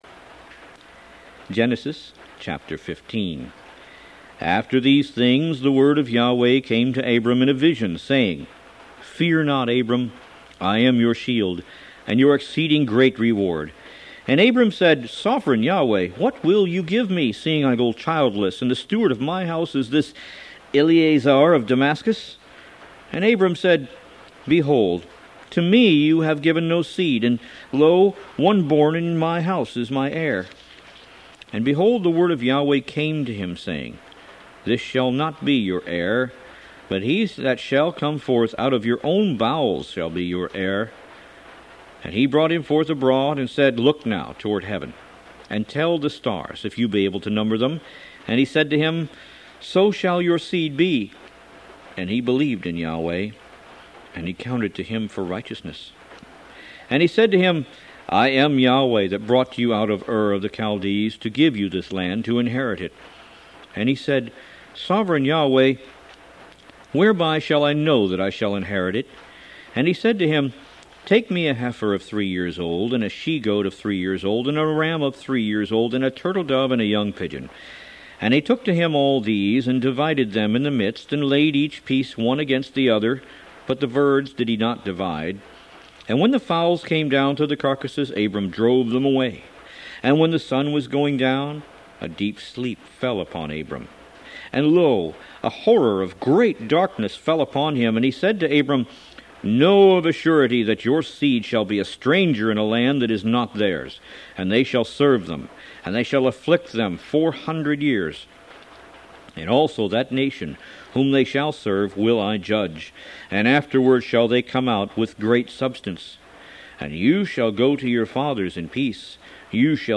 Root > BOOKS > Biblical (Books) > Audio Bibles > Tanakh - Jewish Bible - Audiobook > 01 Genesis